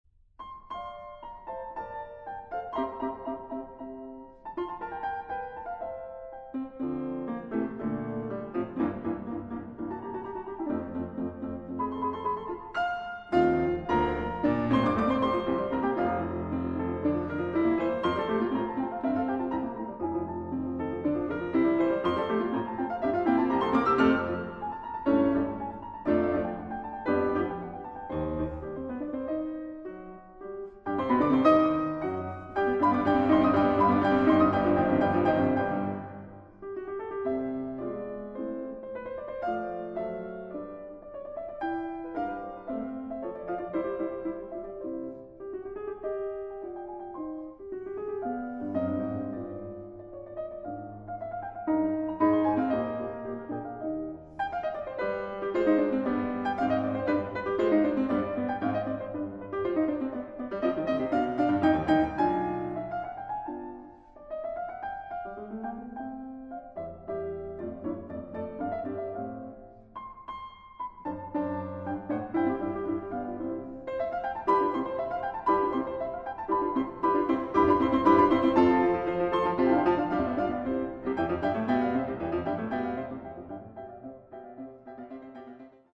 for piano 4 hands